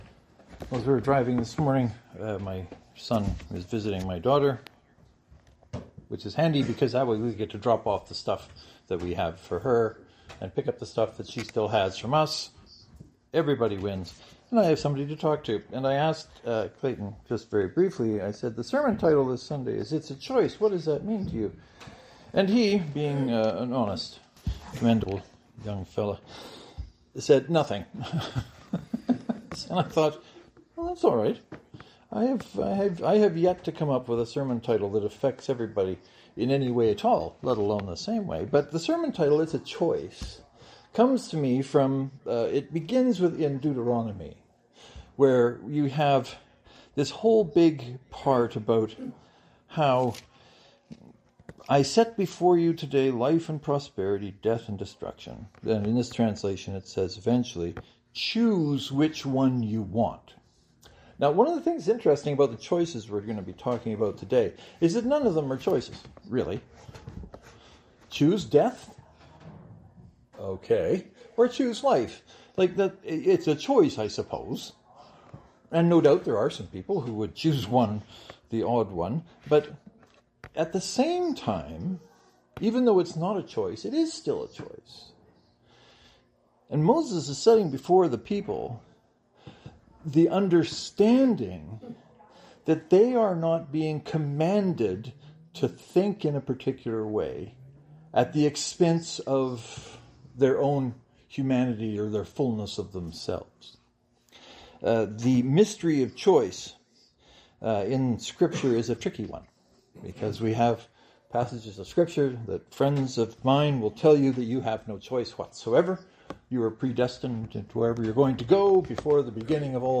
I hope the sermon is of use to you.